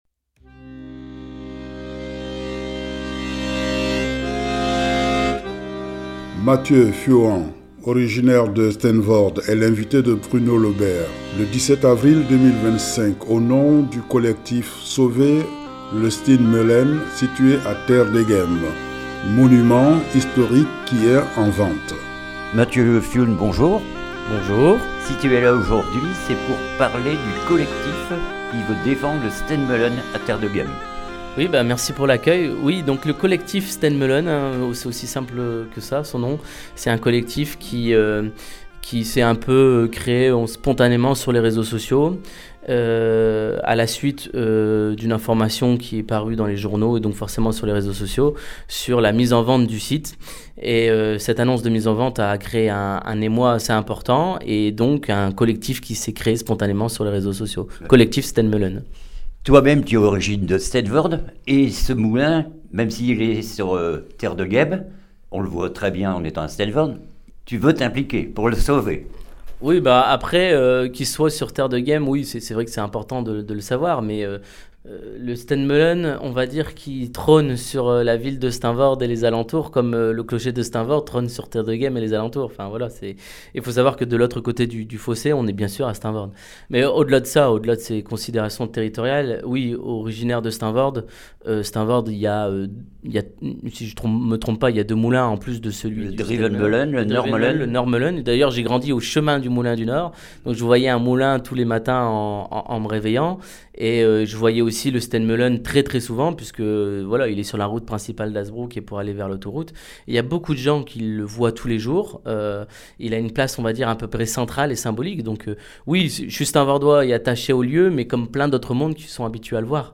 REPORTAGE COLLECTIF STEENMEULEN FRANF